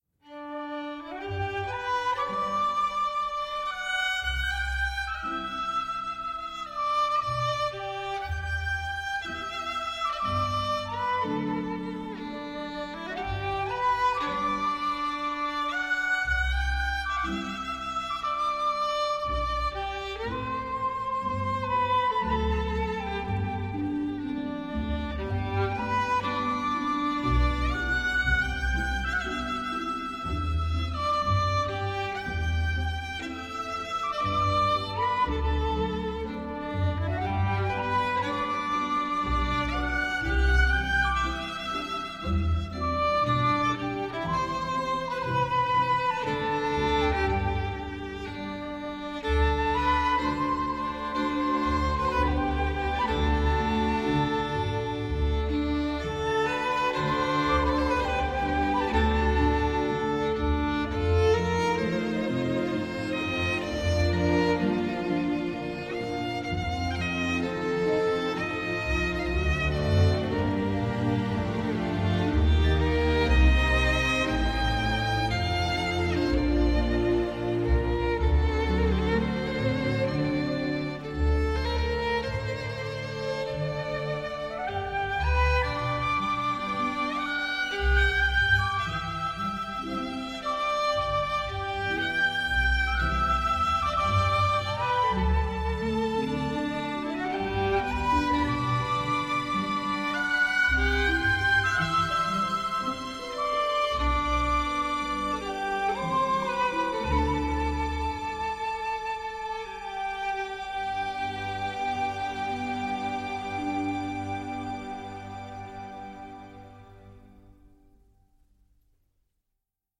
Partition généreuse, souvent vigoureuse
traversé de thèmes galvanisants